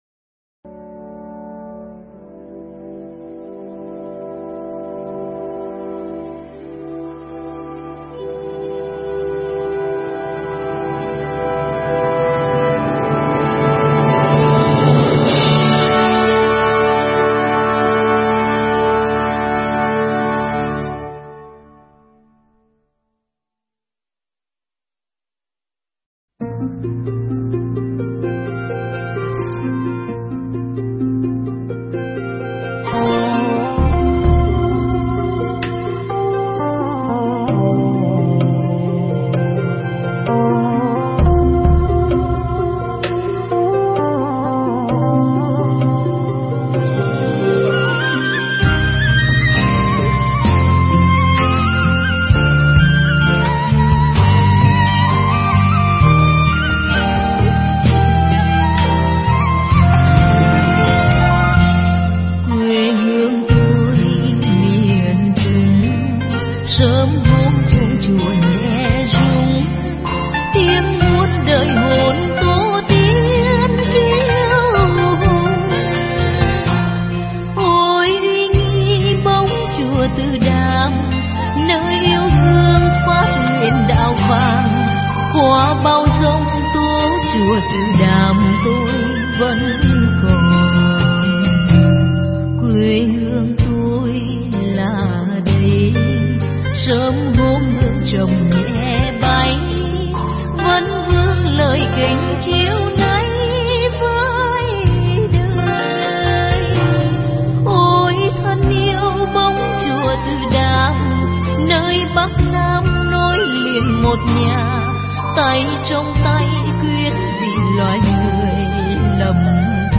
Nghe Mp3 thuyết pháp Hạnh Nguyện Quán Thế Âm Bồ Tát